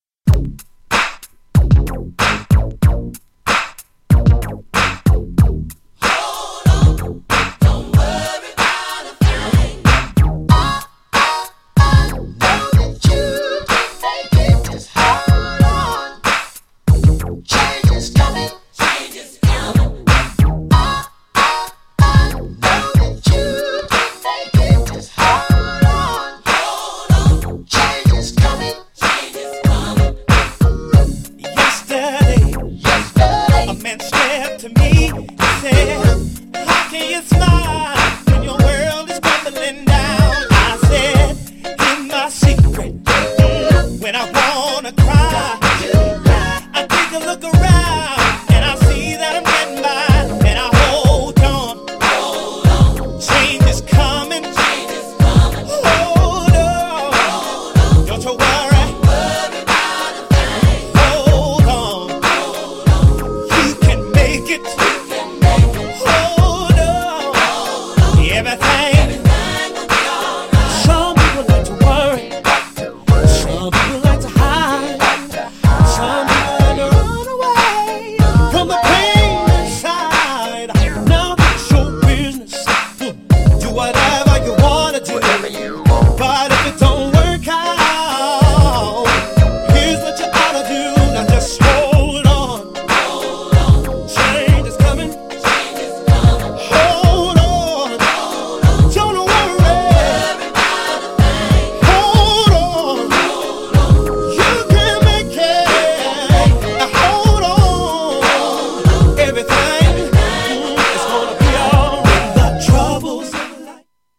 スローテンポからそのままBPMが倍のHOUSEになるドラマティックなMIX!!
GENRE House
BPM 126〜130BPM
ゴスペル
熱いボーカル